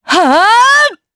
Seria-Vox_Casting4_jp.wav